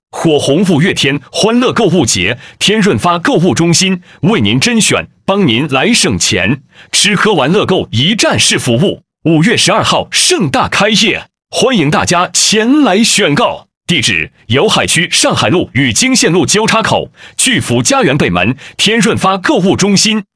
男-058号-列表页